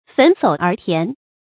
成語字典 >> 焚藪而田 焚藪而田 注音： ㄈㄣˊ ㄙㄡˇ ㄦˊ ㄊㄧㄢˊ 讀音讀法： 意思解釋： 同「焚林而田」。